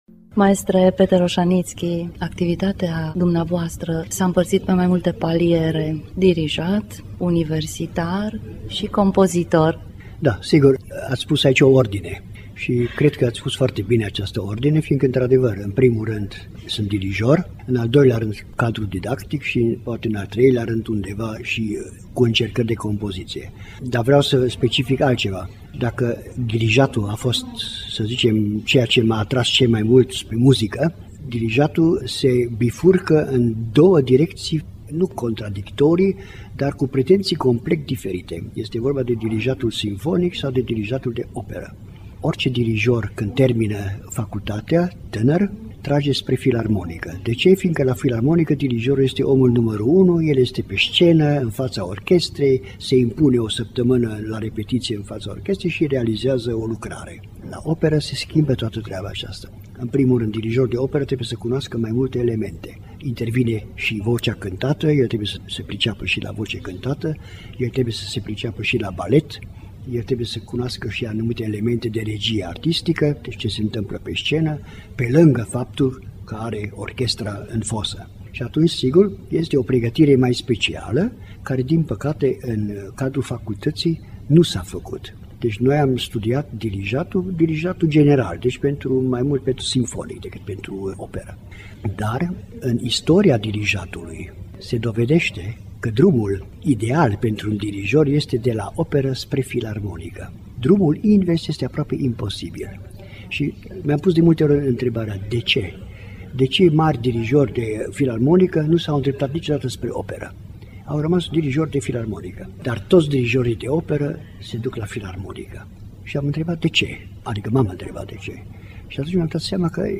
(interviu)